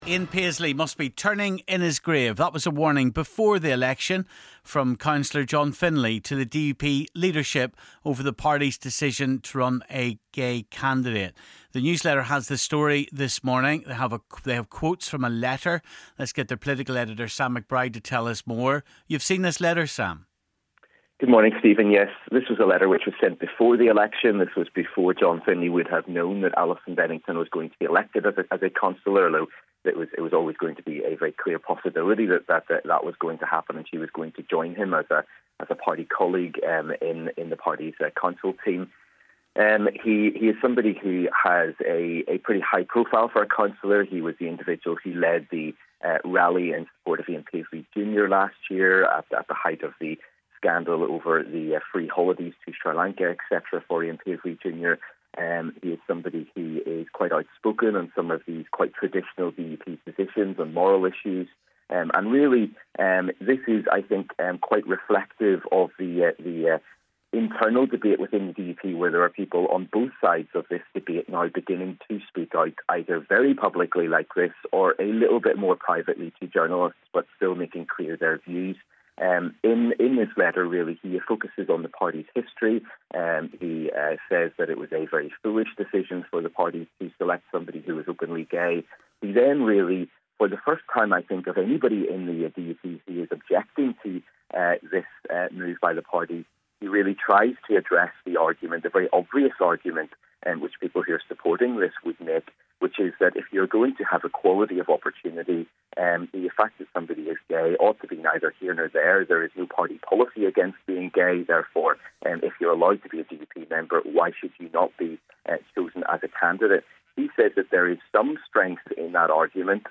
we took your calls